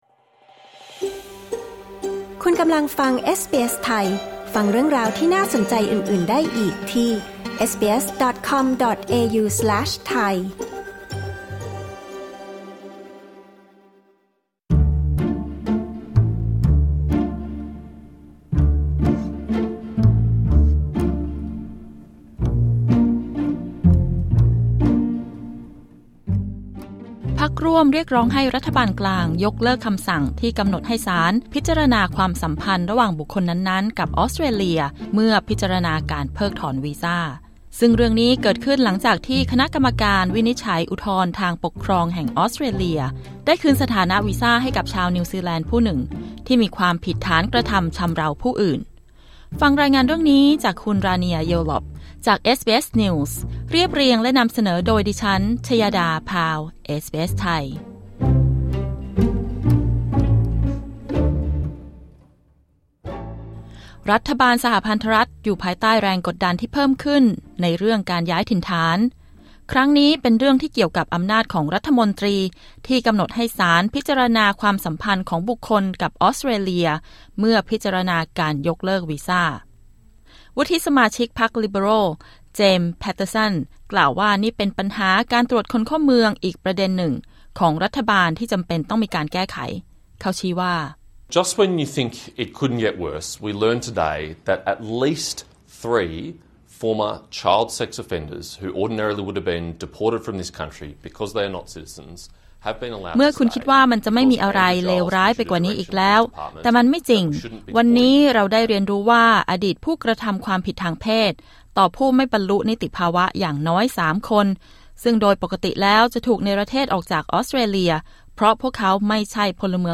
กด ▶ ฟังรายงานข่าวด้านบน